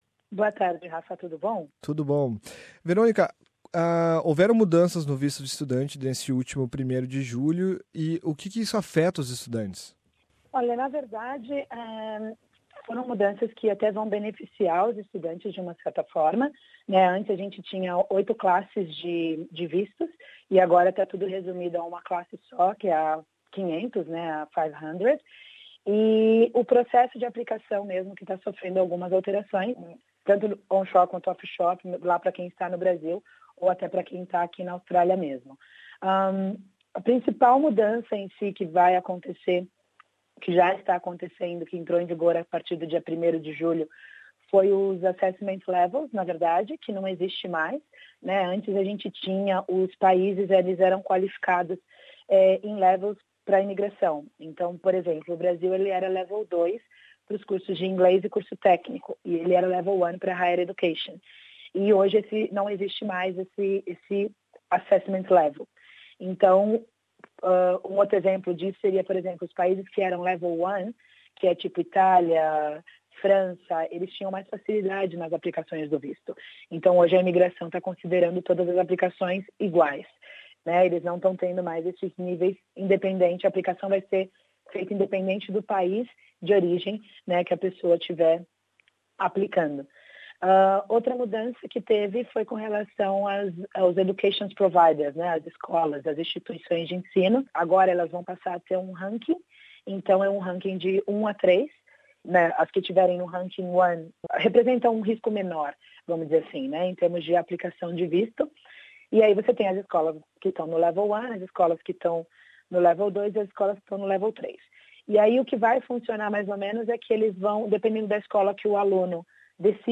O Departamento de Imigração e Proteção de Fronteiras efetuou mudanças no visto de estudante que afetam atuais e futros estudantes internacionais na Australia. As mudanças aconteceram neste primeiro de julho, com base na aplicação, renovação e progressão acadêmica para estudantes internacionais na Australia. Entrevistamos